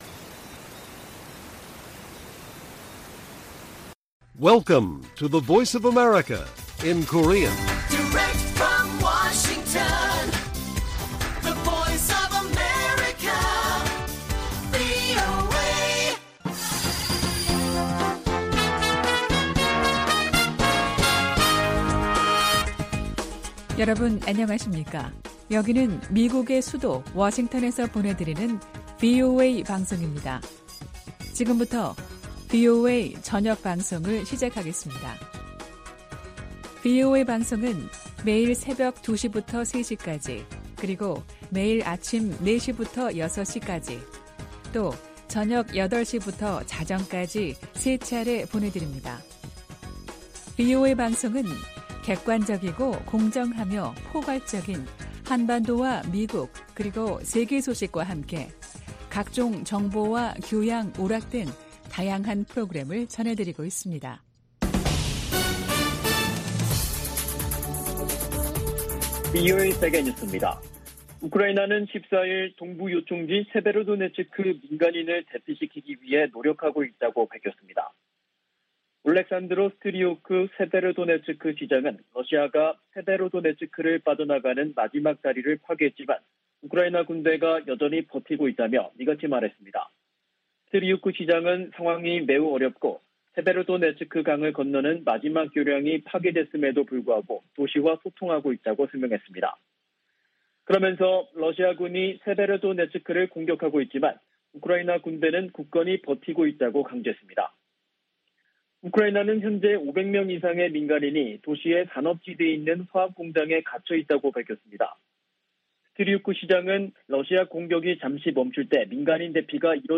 VOA 한국어 간판 뉴스 프로그램 '뉴스 투데이', 2022년 6월 14일 1부 방송입니다. 토니 블링컨 미 국무장관은 북한 핵실험에 단호한 대응을 예고하면서, 대화 응하지 않으면 압박을 강화할 것이라고 경고했습니다. 북한이 '강대강 정면투쟁' 원칙을 내세움에 따라 미-한-일 세 나라는 안보 협력을 강화하는 양상입니다. 북한의 방사포 역량이 핵무기 탑재가 가능한 미사일급으로 증대됐다고 미국의 전문가들이 진단했습니다.